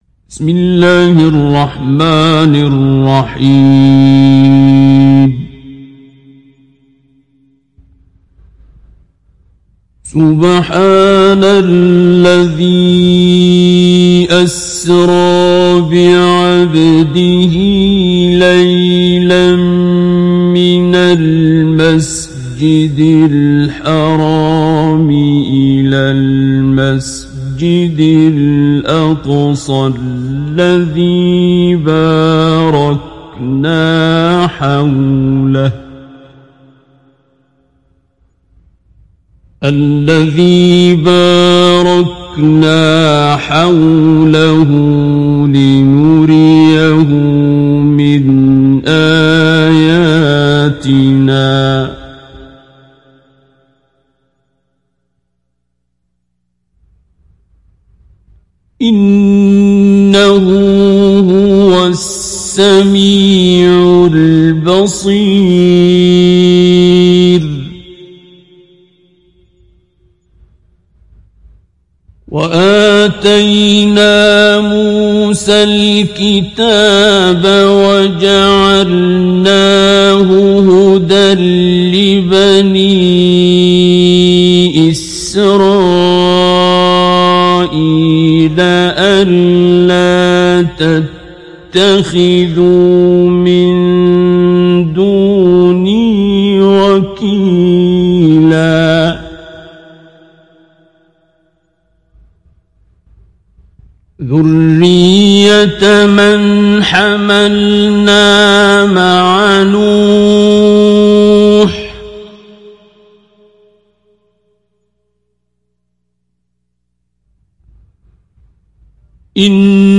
تحميل سورة الإسراء عبد الباسط عبد الصمد مجود